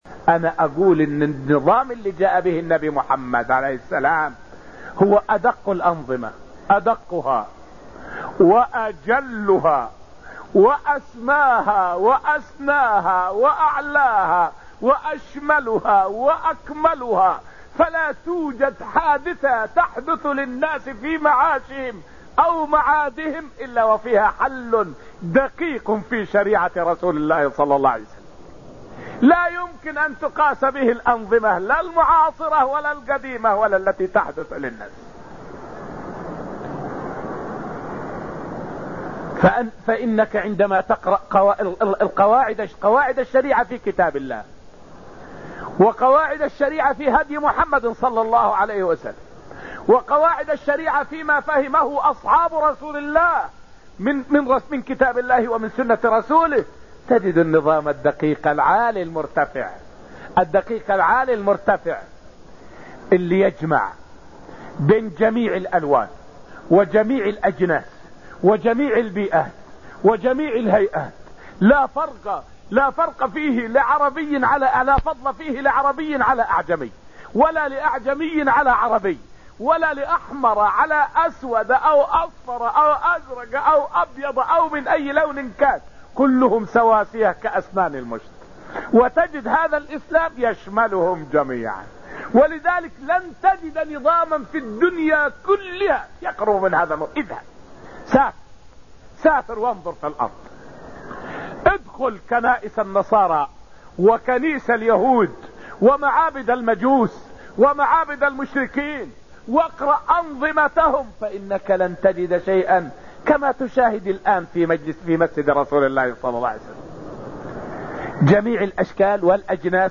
فائدة من الدرس العاشر من دروس تفسير سورة الأنفال والتي ألقيت في رحاب المسجد النبوي حول فضل الشريعة المحمدية على شرائع الأنبياء السابقة.